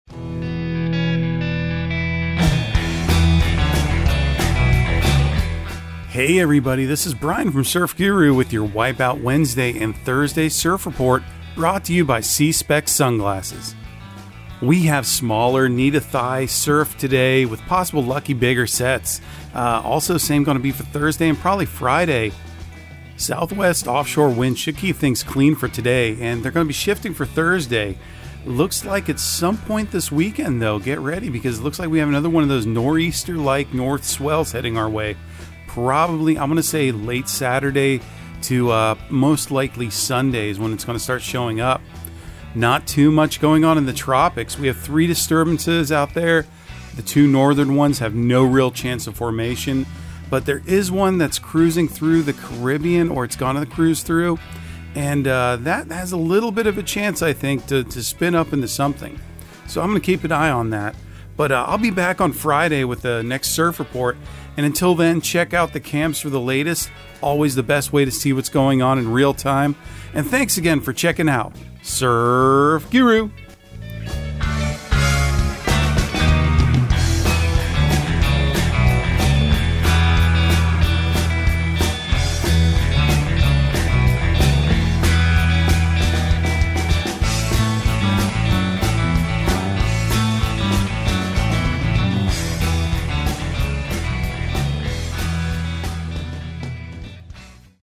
Surf Guru Surf Report and Forecast 10/26/2022 Audio surf report and surf forecast on October 26 for Central Florida and the Southeast.